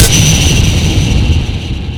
sonarTailWaterVeryClose1.ogg